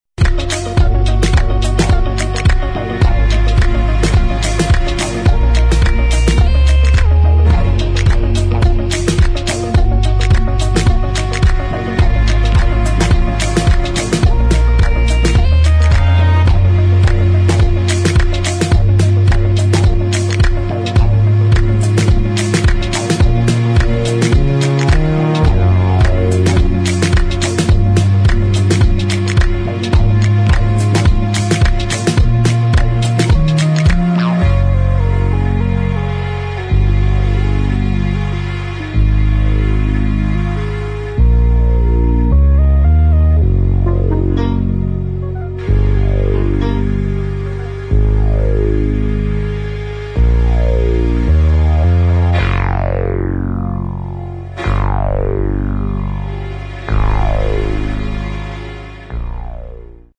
[ DOWNTEMPO | ELECTRONIC ]